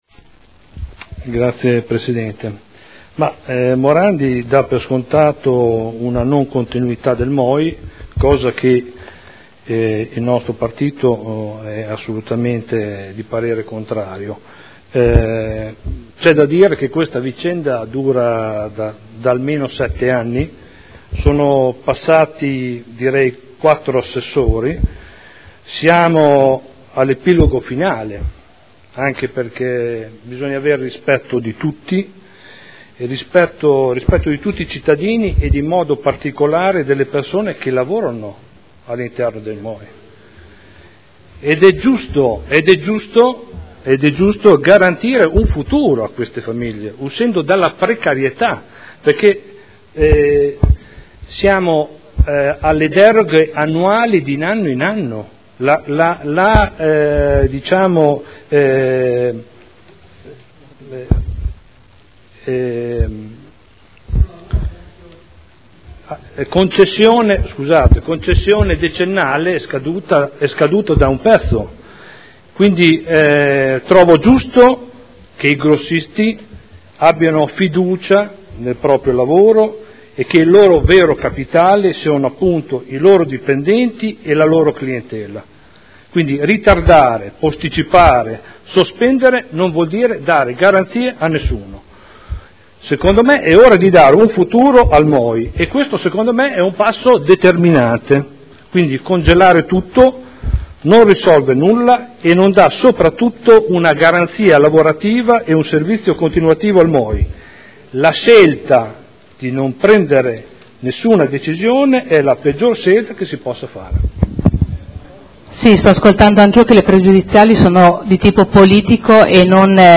Seduta del 18/06/2012. Dibattito su pregiudiziale alla proposta di deliberazione.